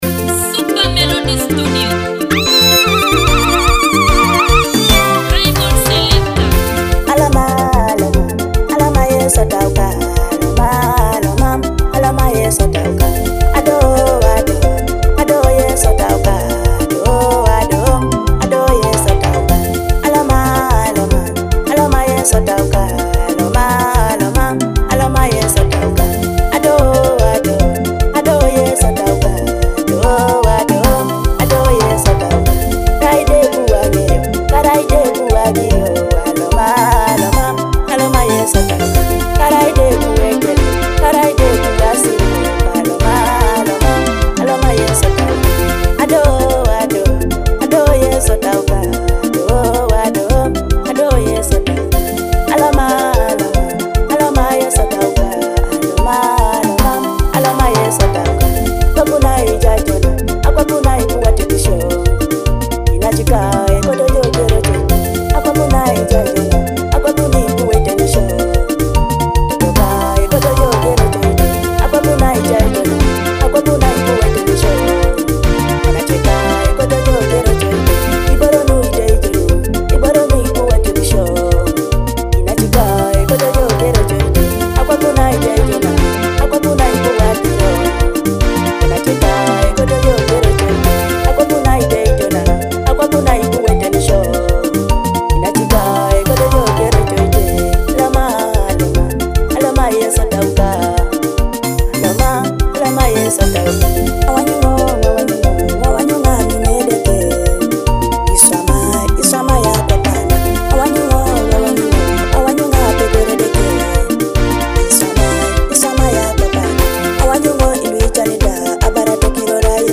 uplifting gospel worship song